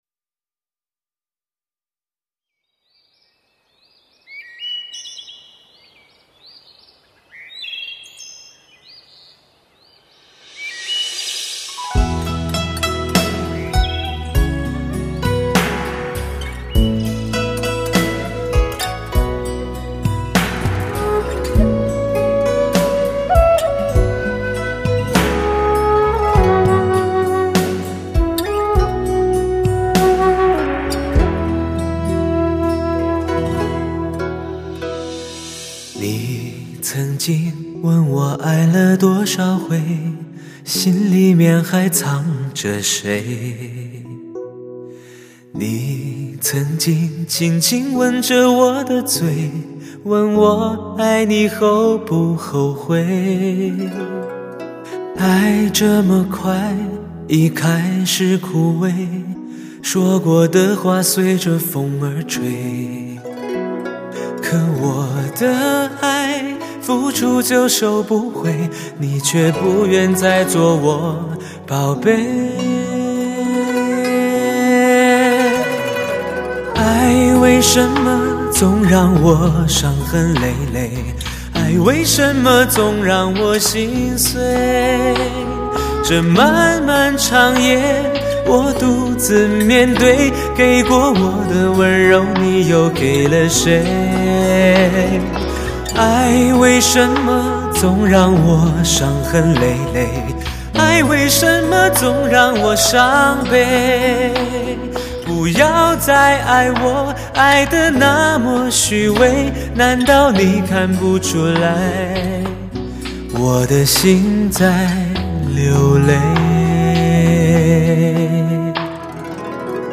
既保留了黑胶LP唱盘的高保真与自然感
又具备了CD的高清晰与低噪音的优点
音色醇厚、干净、细腻、通透，是发烧界男声的完美综合！